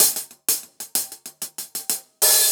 Index of /musicradar/ultimate-hihat-samples/95bpm
UHH_AcoustiHatC_95-05.wav